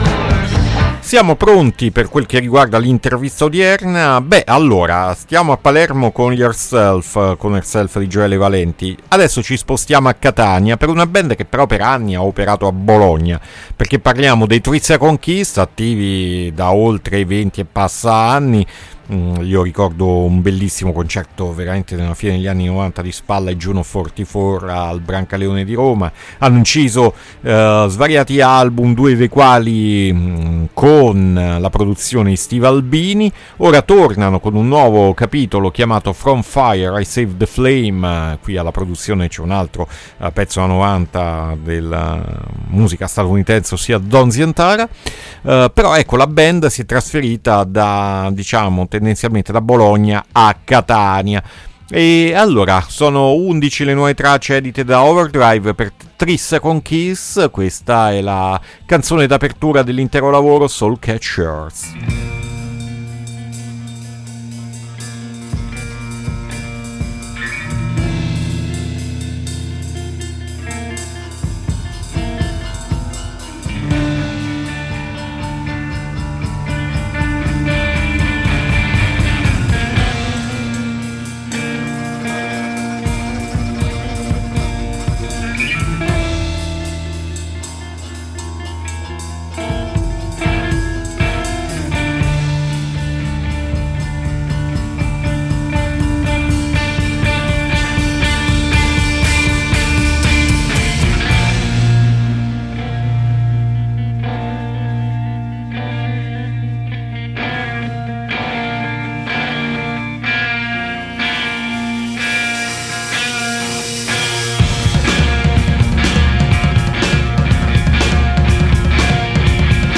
INTERVISTA THREE SECOND KISS AD ALTERNITALIA 7-6-2024